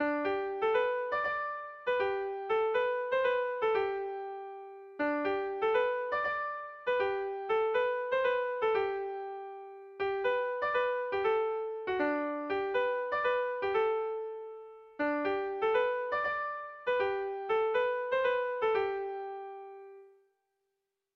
Gabonetakoa
Zortziko txikia (hg) / Lau puntuko txikia (ip)
AABA